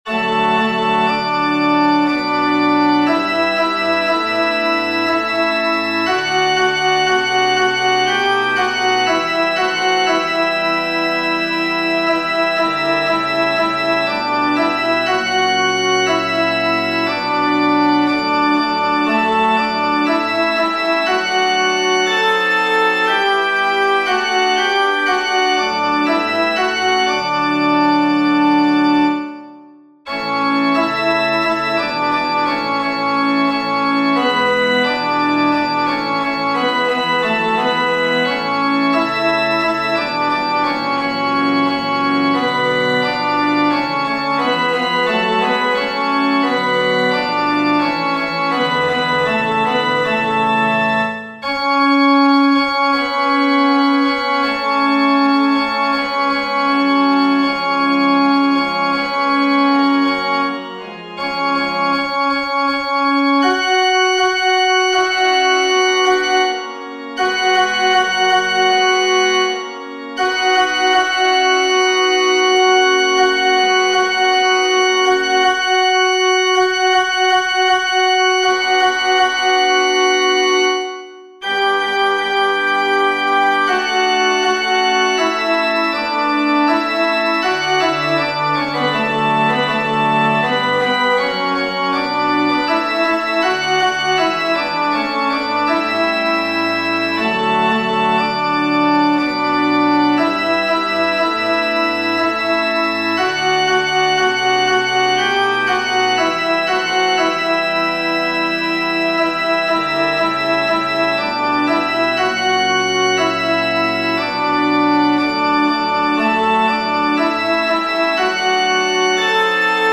FF:HV_15b Collegium male choir